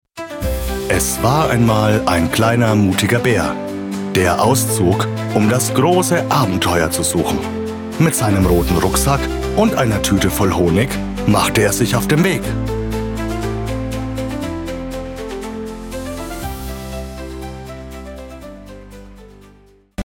Hörbücher: „Kinderbuch-Zauber“